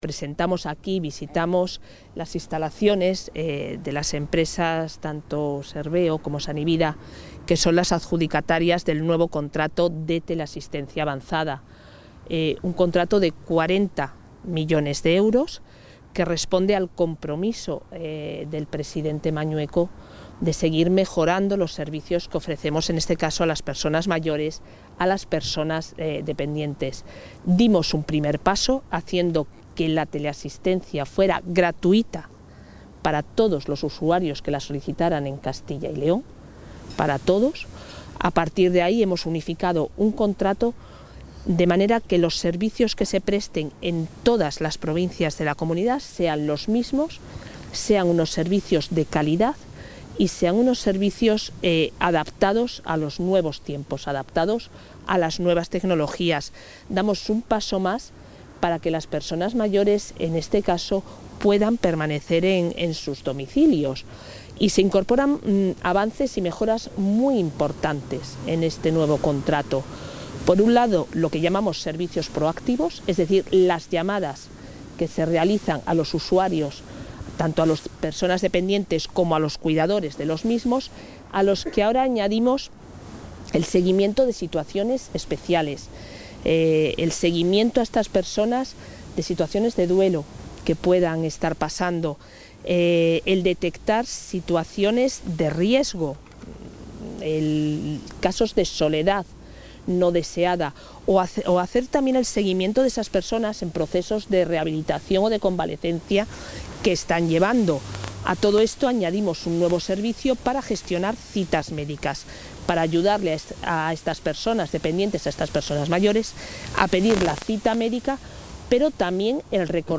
Intervención de la consejera.